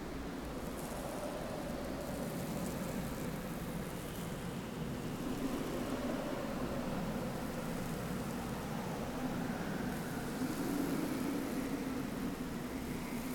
Desert_wind.ogg